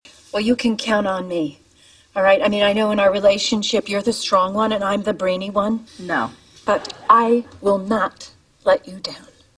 Category: Television   Right: Personal
Tags: The New Adventures of Old Christine The New Adventures of Old Christine clips Sitcom Comedy Julia Louis-Dreyfus